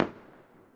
beta-pylene break
Tap4.mp3